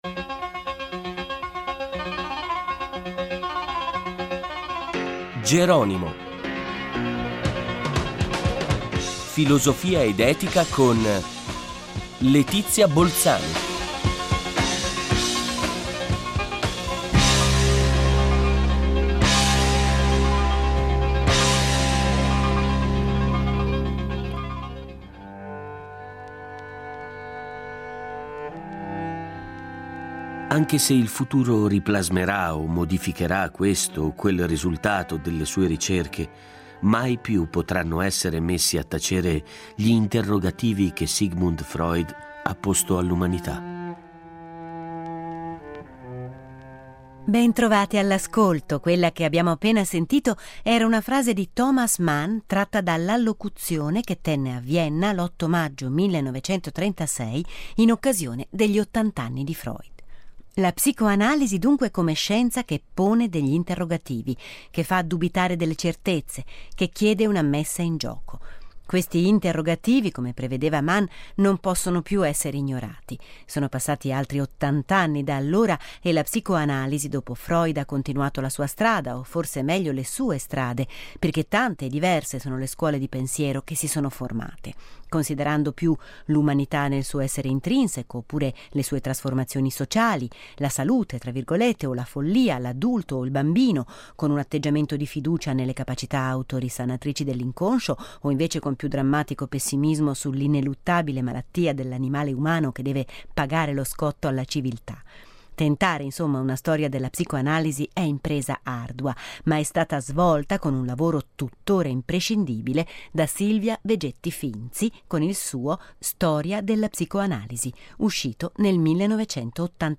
Incontro con Silvia Vegetti Finzi